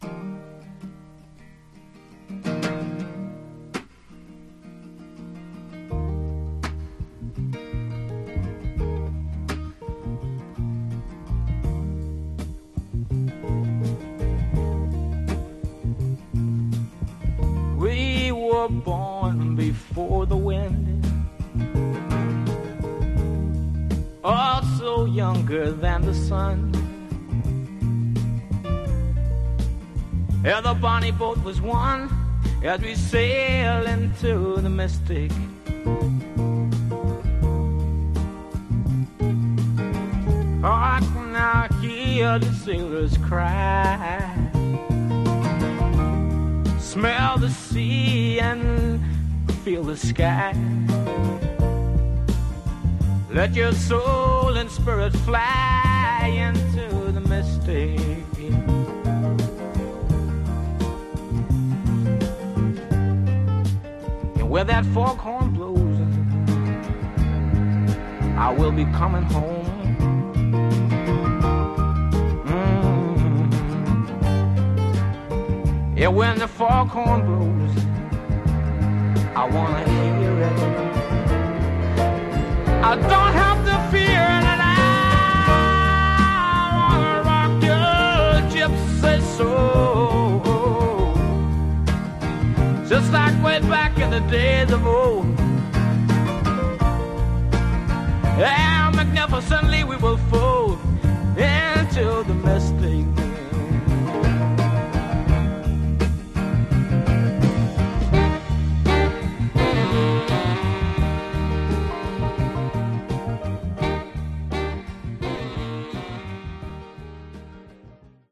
Genre: Funky Soul